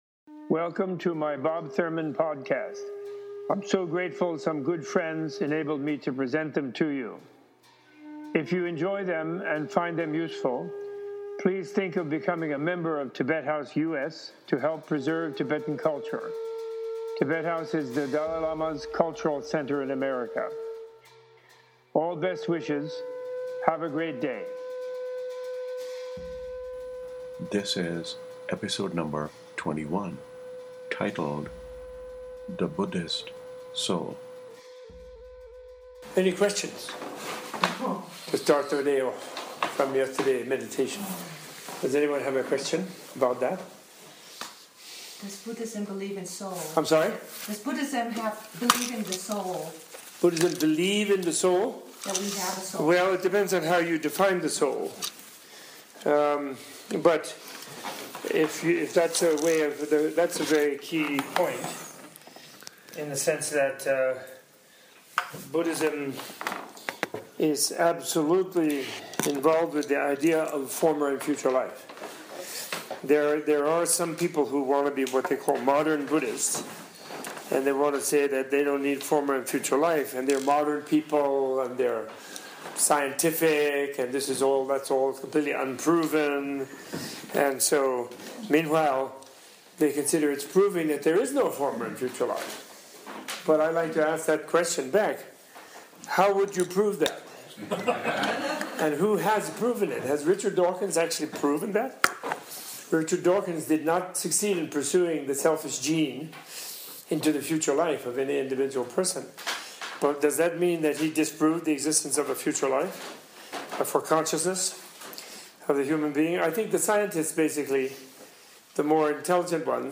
In a concise and humorous way, Professor Thurman explains the Buddhist understanding of existence, non-existence, and what is reborn.